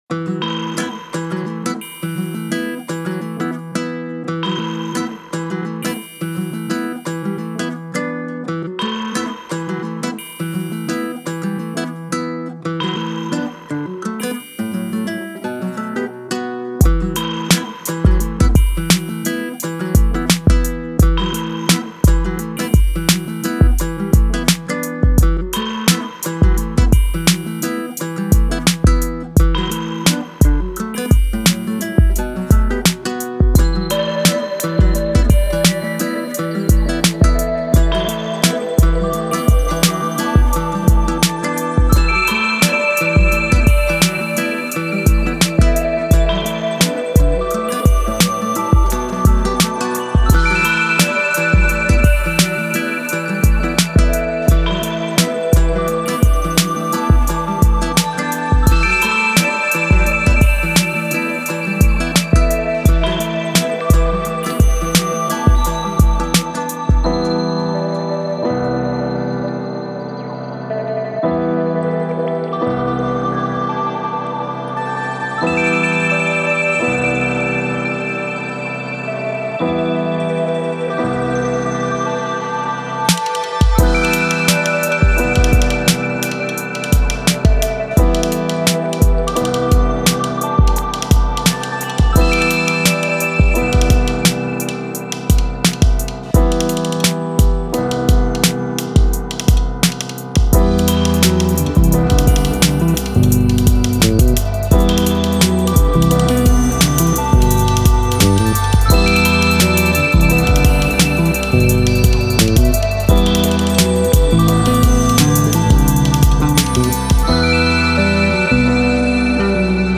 with a specific taste for jazz as well as electronic music.
organically swinging sound